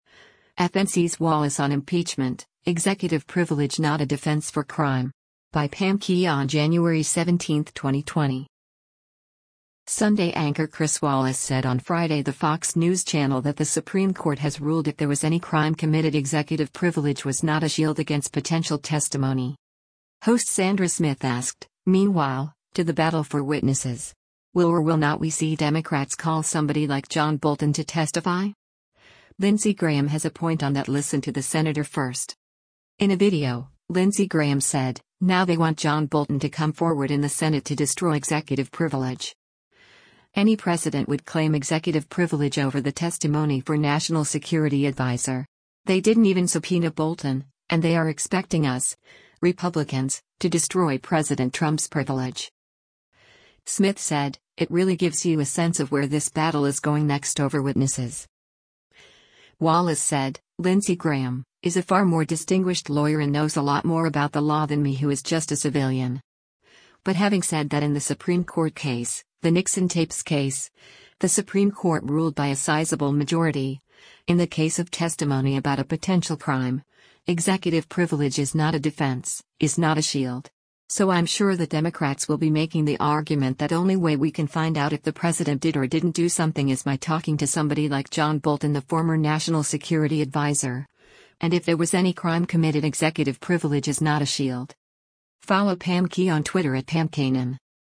Sunday anchor Chris Wallace said on Friday the Fox News Channel that the Supreme Court has ruled if there was any crime committed executive privilege was not a shield against potential testimony.